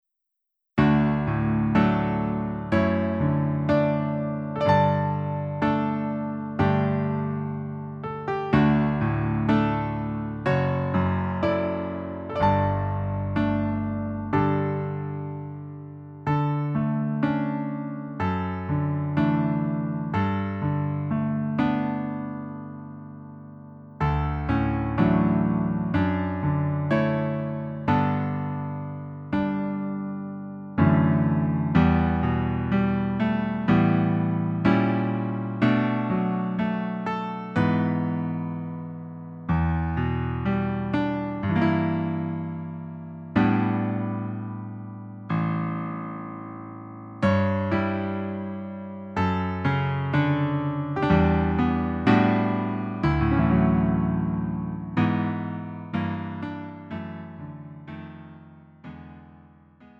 음정 -1키
장르 가요 구분 Lite MR